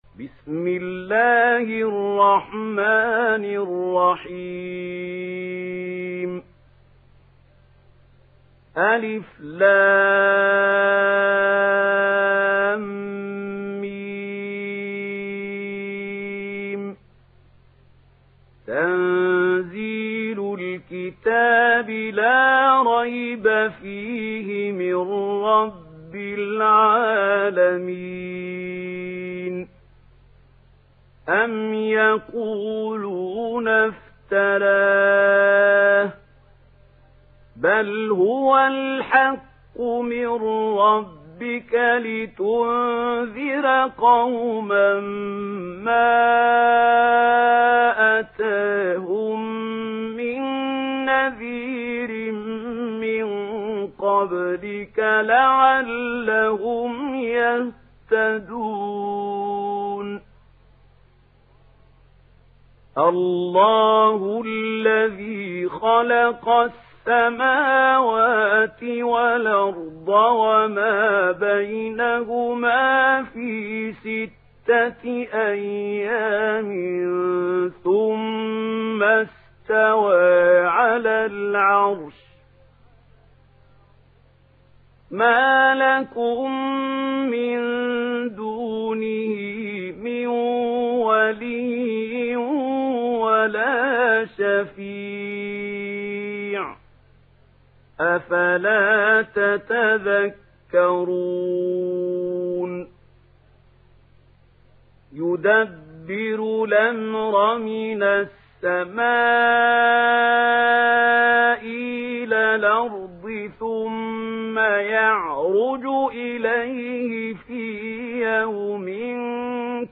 تحميل سورة السجدة mp3 بصوت محمود خليل الحصري برواية ورش عن نافع, تحميل استماع القرآن الكريم على الجوال mp3 كاملا بروابط مباشرة وسريعة